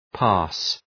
{pɑ:rs}